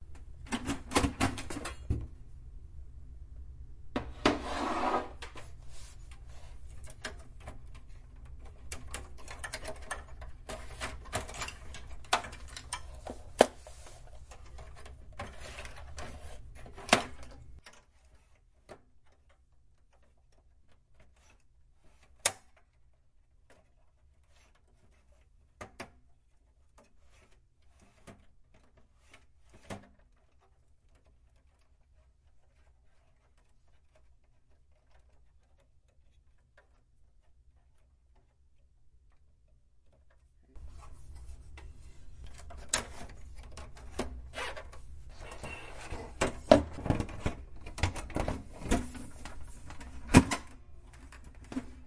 感应线圈录音，2014年9月 " hdd外部文件夹访问长
描述：使用感应线圈记录的外部3.5英寸USB硬盘驱动器上的数据访问时间更长。
标签： 场记录 硬盘 HDD 感应线圈 科幻 硬盘驱动器
声道立体声